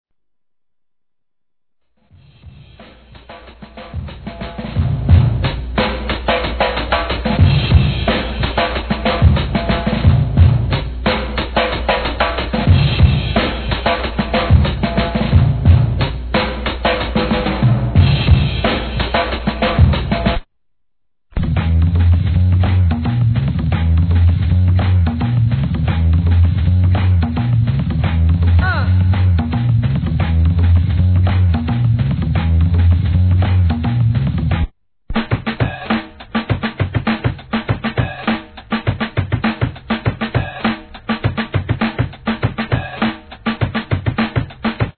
HIP HOP/R&B
ブレイクビーツ・コスリネタ No. タイトル アーティスト 試聴 1.